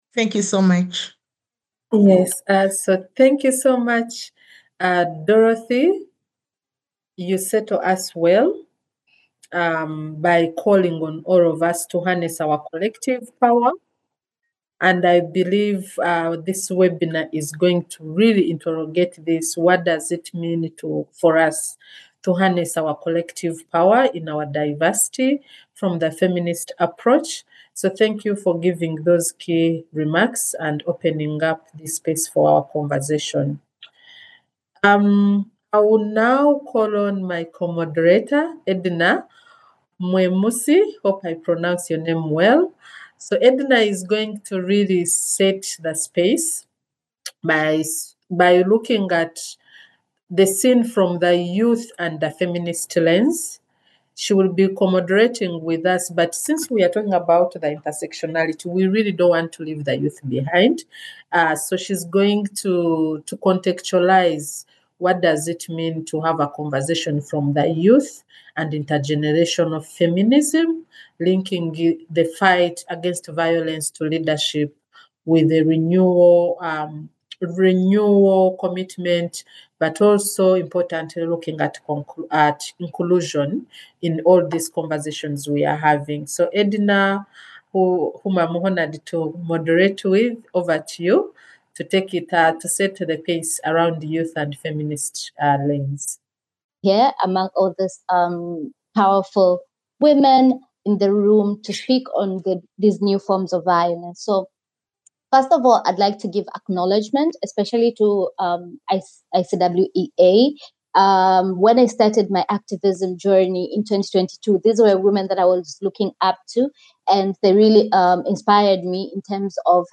Webinar: Shifts in Ending New and Evolving Forms of Violence Part 2 – International Community of Women living with HIV Eastern Africa
Cross-Country-Webinar-on-EVAWG-Part-TWO.mp3